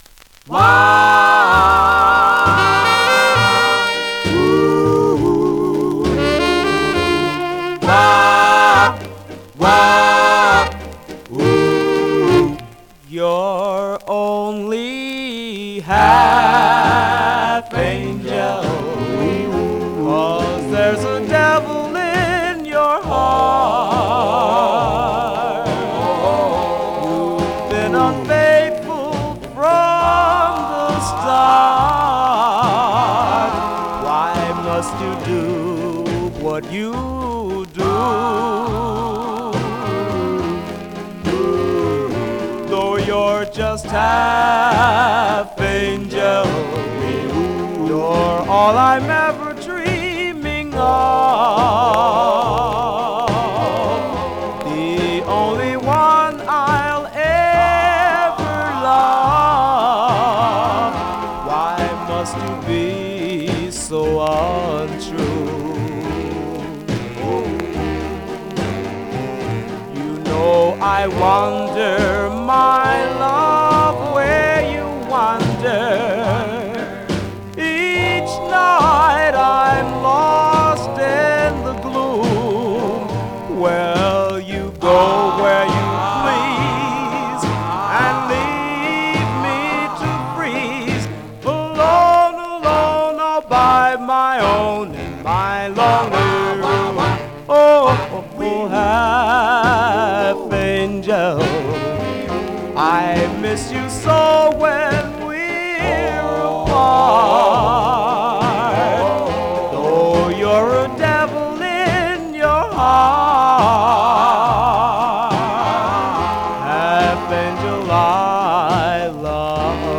Surface noise/wear
Mono
Male Black Group Condition